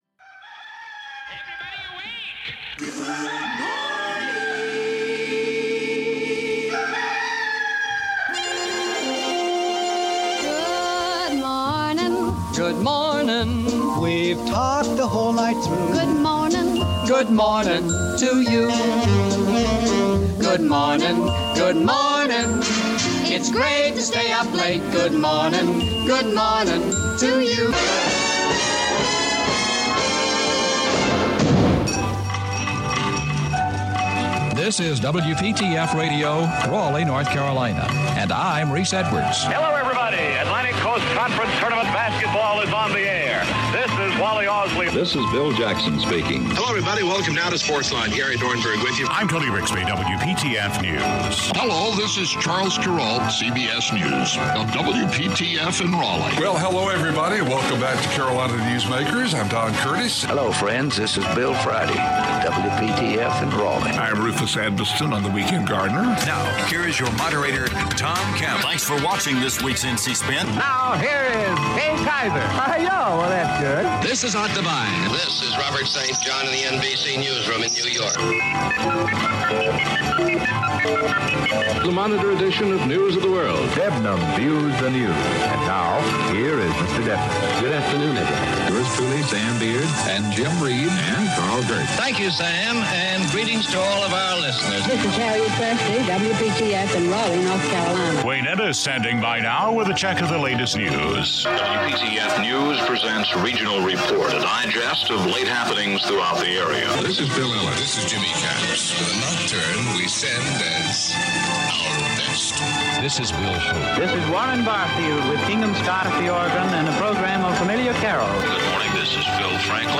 The WPTF Weekend Gardener is an award-winning radio talk show that has been on the air for over 30 years.